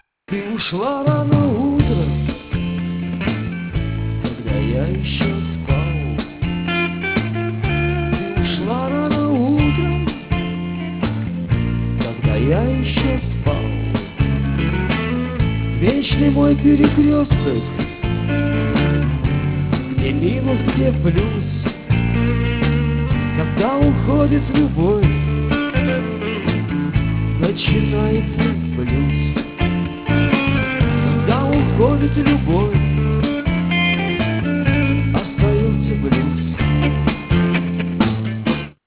AUDIO, stereo